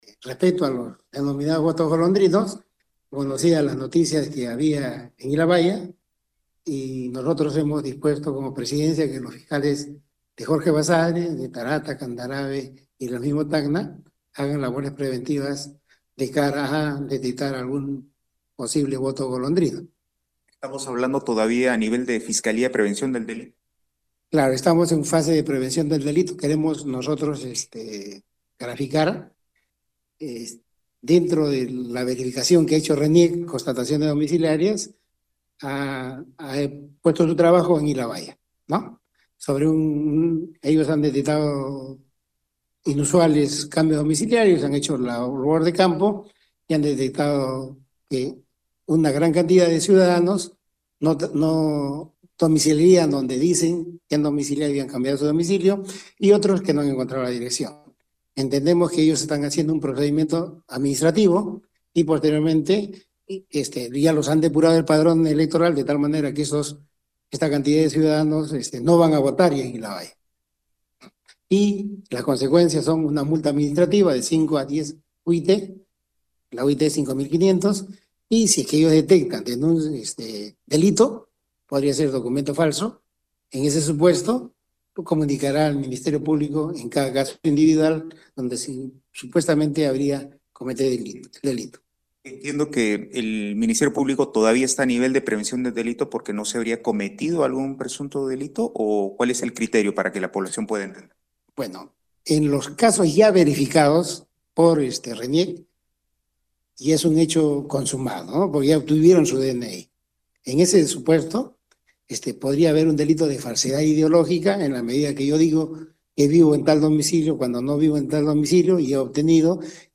En una entrevista reciente, Walter Goyzueta, presidente de la Junta de Fiscales, informó que la Fiscalía de Prevención del Delito ya ha solicitado formalmente a Reniec información sobre incrementos inusuales de domicilio en otros distritos de la región.